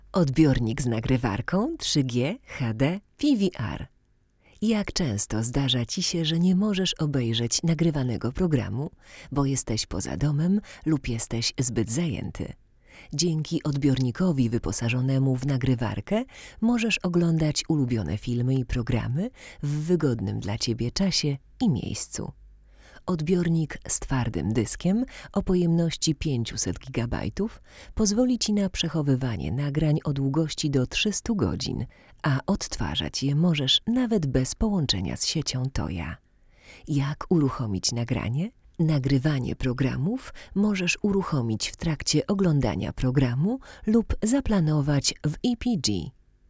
Do testów używamy popularnego Neumanna TLM 103, i dla równowagi U89 tego samego producenta.
Brzmienie tego przedwzmacniacza naprawdę nie pozostawia wiele do życzenia.